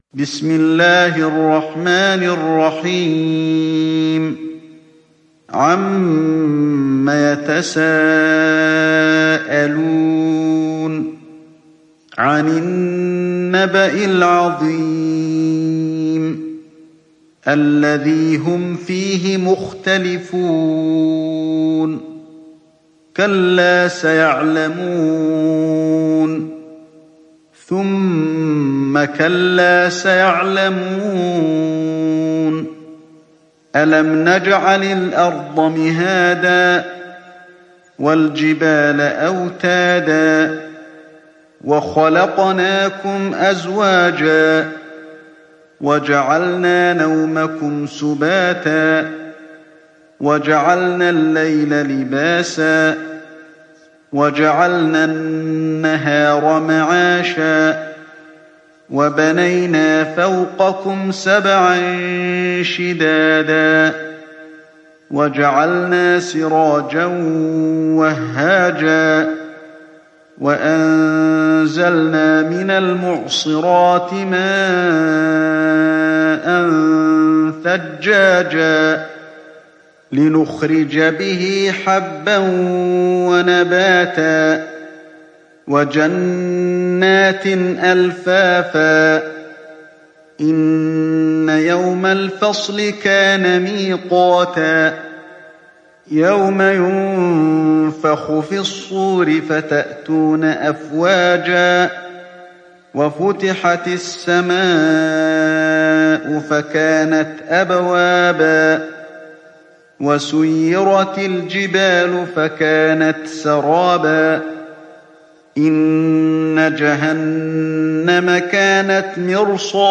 Sourate An Naba Télécharger mp3 Ali Alhodaifi Riwayat Hafs an Assim, Téléchargez le Coran et écoutez les liens directs complets mp3